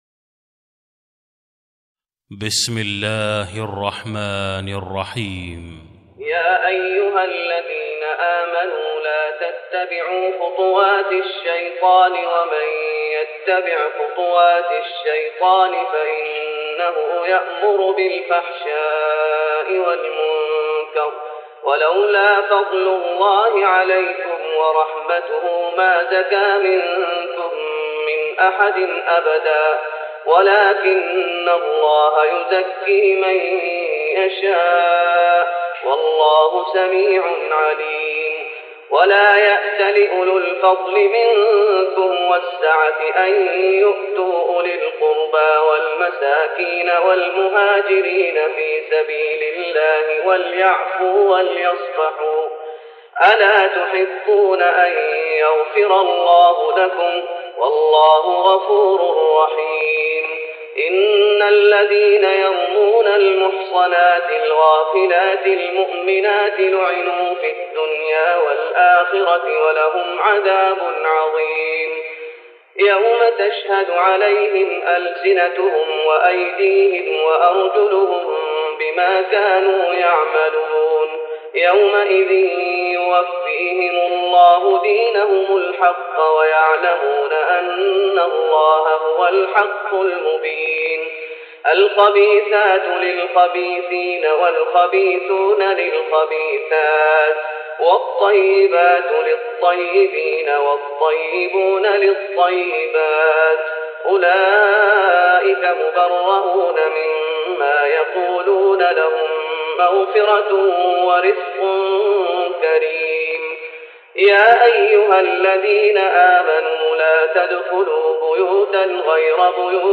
تراويح رمضان 1414هـ من سورة النور (21-50) Taraweeh Ramadan 1414H from Surah An-Noor > تراويح الشيخ محمد أيوب بالنبوي 1414 🕌 > التراويح - تلاوات الحرمين